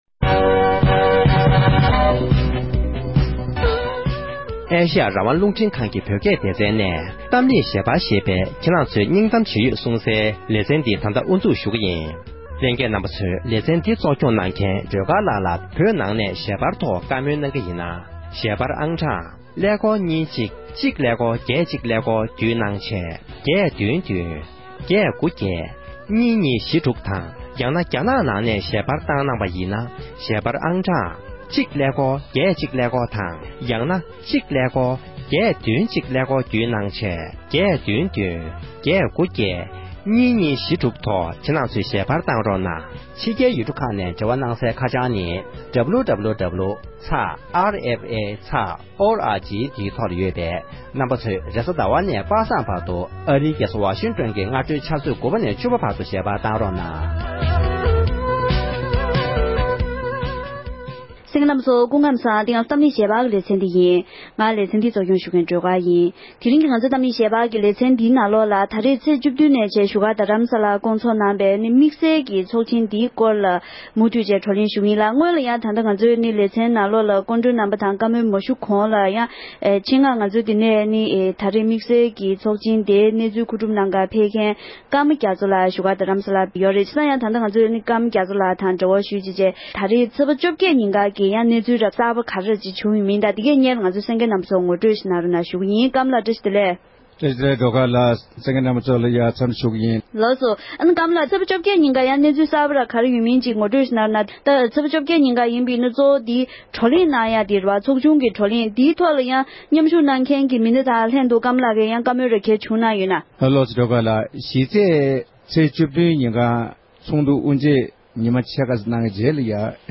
དེ་རིང་གི་གཏམ་གླེང་ཞལ་པར་གྱི་ལེ་ཚན་